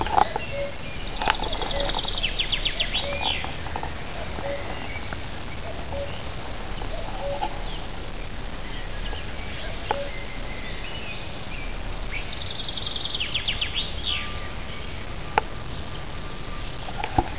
Abb. 01: Vogelstimmen im Wald.
Wald